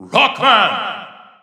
The announcer saying Mega Man's name in Japanese and Chinese releases of Super Smash Bros. 4 and Super Smash Bros. Ultimate.
Mega_Man_Japanese_Announcer_SSB4-SSBU.wav